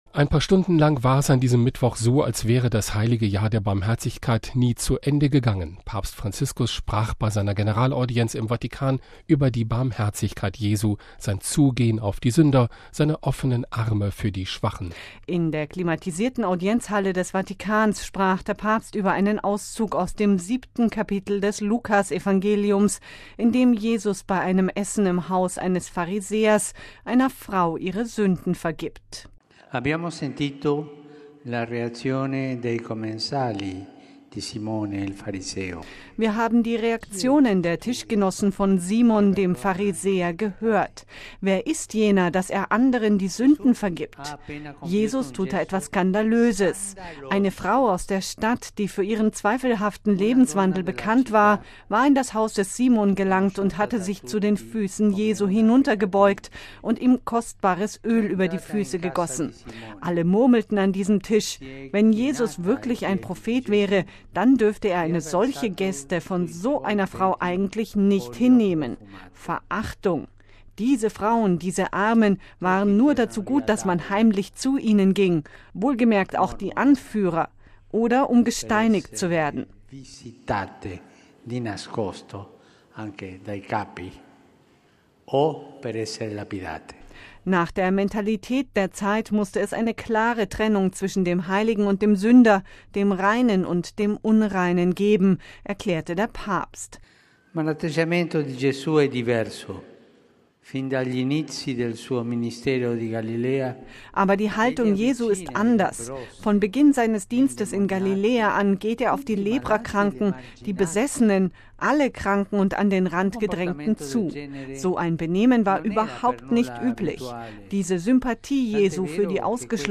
Generalaudienz: Jesus will unsere Befreiung
In der klimatisierten Audienzhalle des Vatikans sprach der Papst über einen Auszug aus dem siebten Kapitel des Lukas-Evangeliums, in dem Jesus bei einem Essen im Haus eines Pharisäers einer Frau ihre Sünden vergibt.